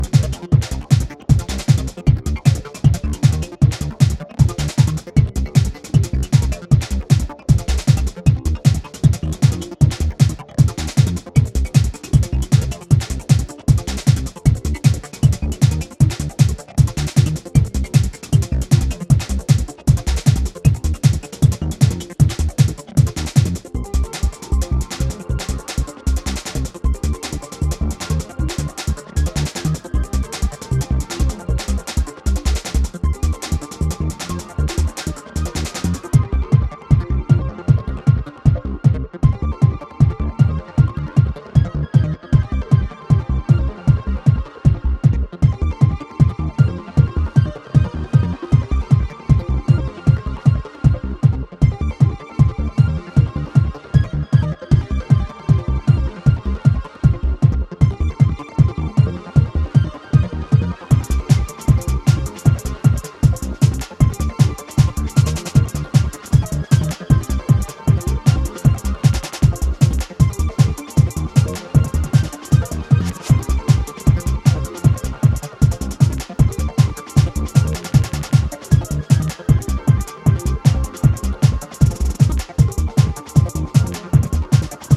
Spanning Electro, Drum 'n' Bass, Dark-Wave, and Ambient